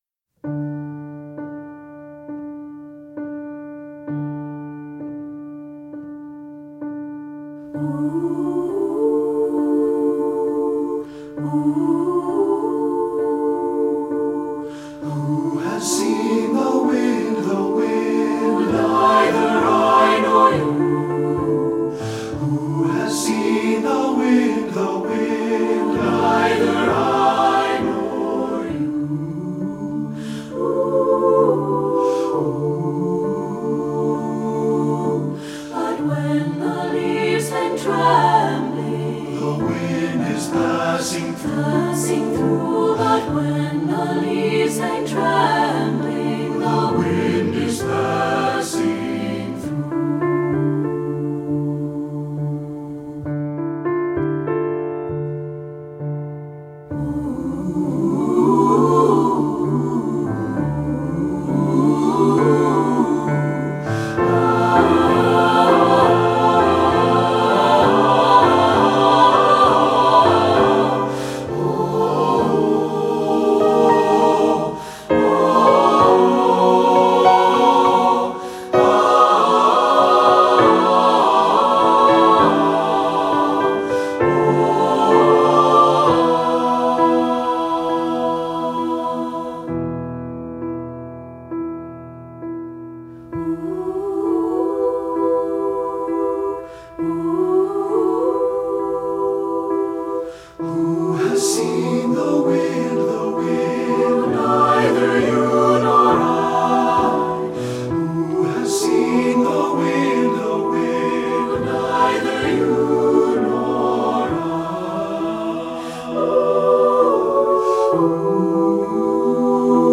Voicing: SSAB and Piano